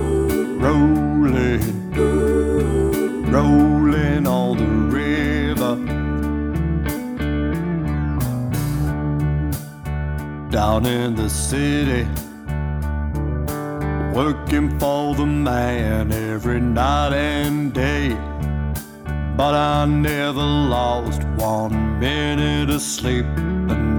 For Solo Female Pop (1980s) 5:28 Buy £1.50